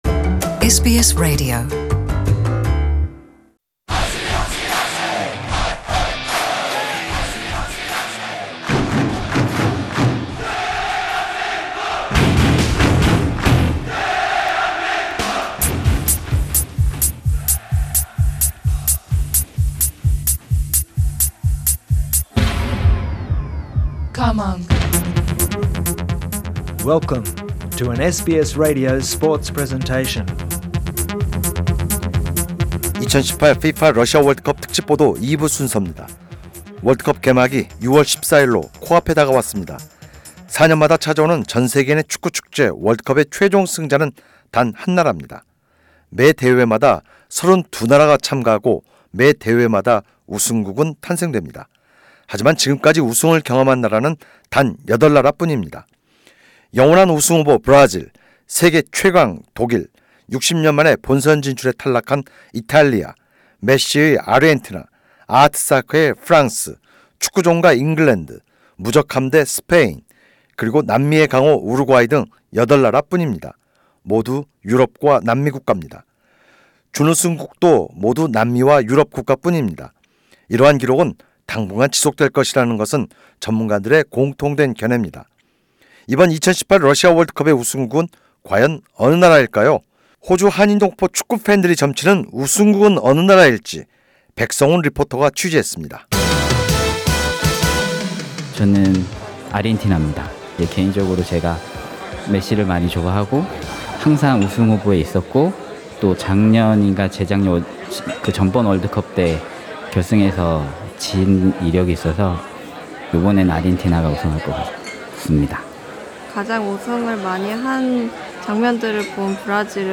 SBS Radio Korean Progam conducted the voxpop with Korean Australian soccer fans to hear about their prections on the teams most capable of becoming world champions in 2018.